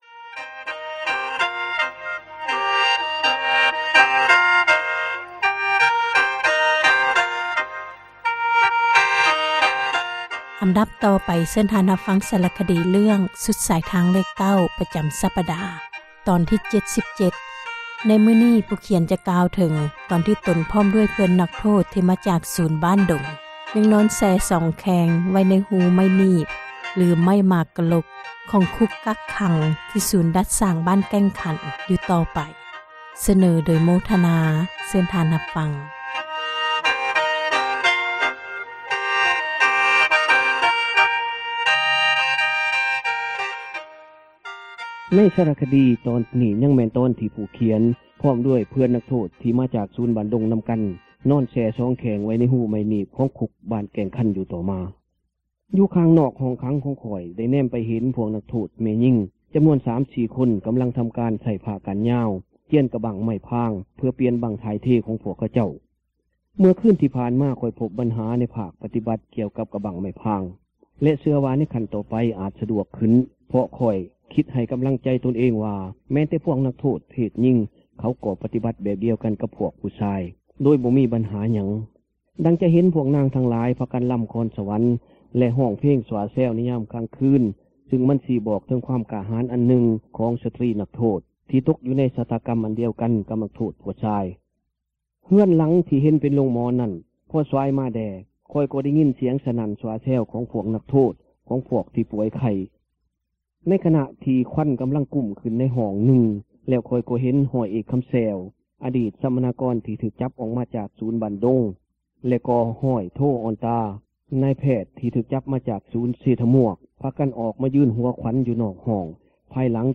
ສາຣະຄະດີ ເຣື້ອງ ສຸດສາຍທາງເລຂ 9, ຕອນທີ 77 ໃນມື້ນີ້ ຜູ້ຂຽນ ຈະເວົ້າເຖິງ ຕອນທີ່ ຕົນເອງ ພ້ອມດ້ວຍ ເພື່ອນນັກໂທດ ທີ່ມາຈາກສູນ ສັມມະນາ ບ້ານດົງ ນໍາກັນນັ້ນ ຍັງນອນ ເອົາສອງແຂ່ງ ແຊ່ໄວ້ໃນ ຮູໄມ້ໜີບ (ໄມ້ໝາກກະລົກ)  ຂອງຄຸກກັກຂັງ ທີ່ ສູນດັດສ້າງ ບ້ານແກ້ງຄັນ...